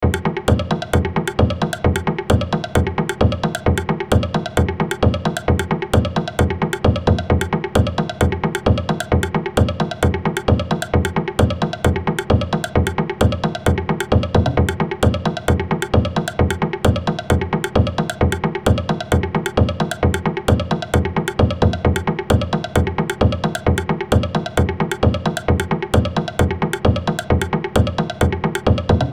テンポのいい木製の打楽器ウッドブロックの音。